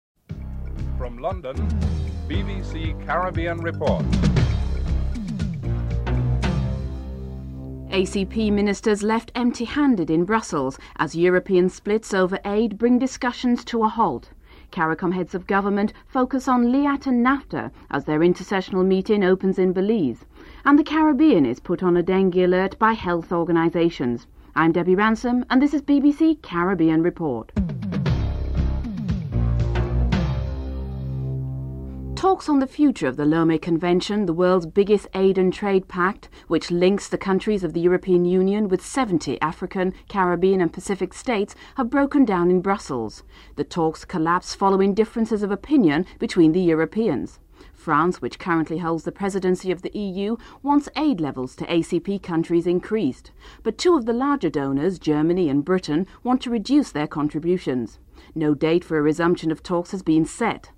5. Recap of top stories (14:24-14:41)